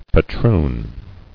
[pa·troon]